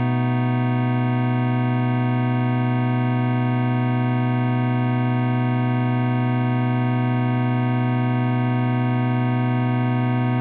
b7-chord.ogg